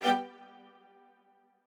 strings5_2.ogg